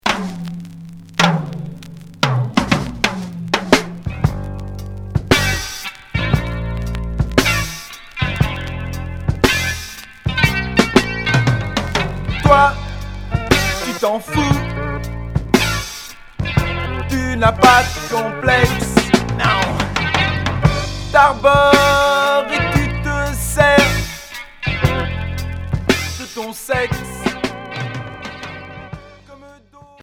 Pop rock Unique 45t retour à l'accueil